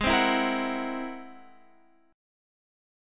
G# Augmented 9th